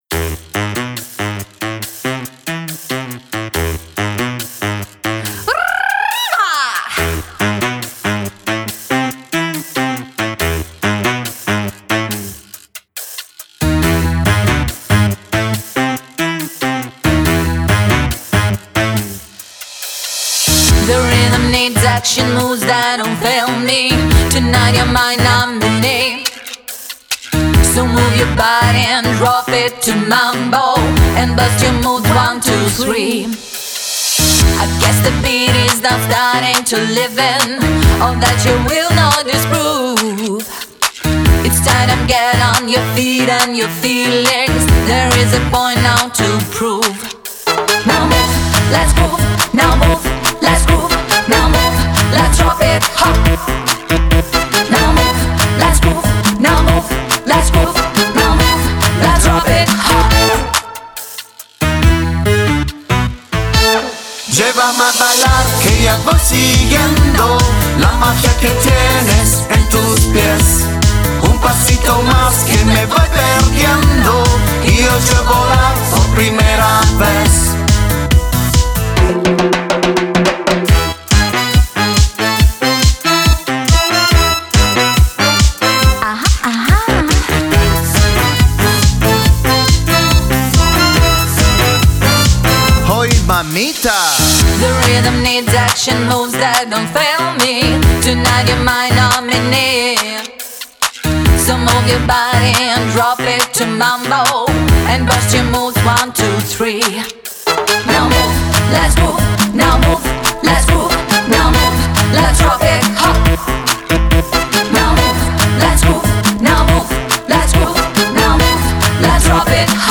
Latin
mambo-pop